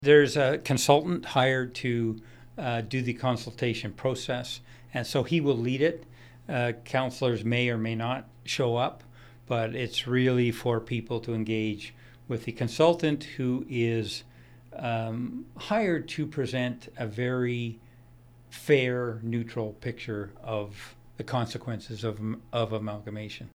Mayor of the Municipality of North Cowichan, Jon Lefebure…..